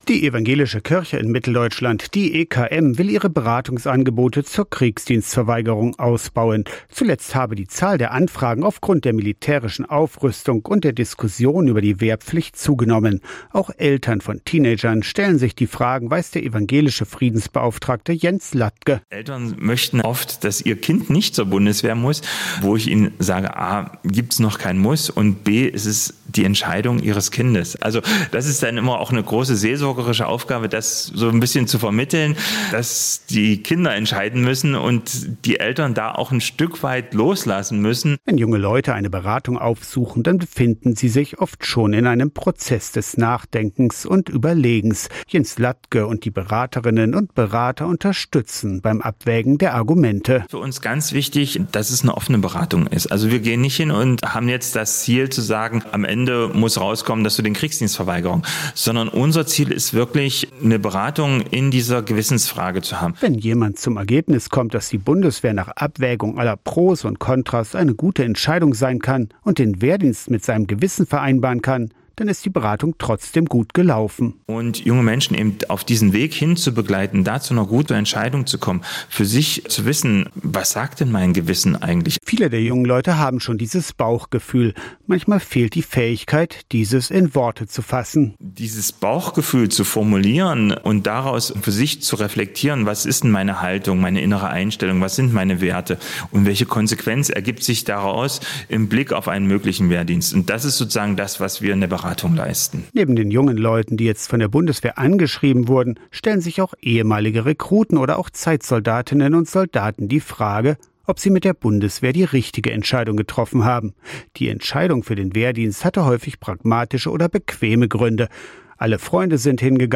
Interviewte